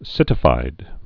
(sĭtĭ-fīd)